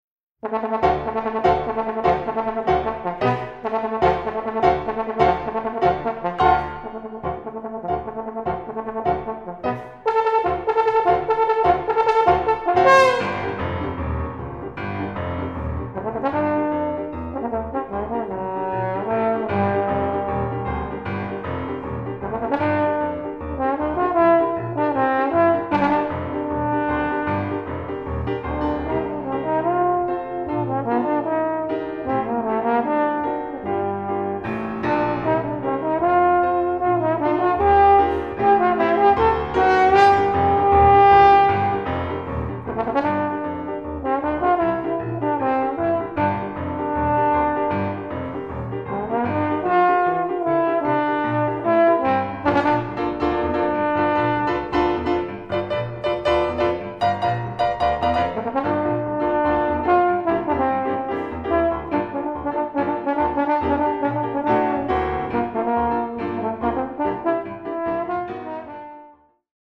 Klavier
Posaune
Concerto for Trombone: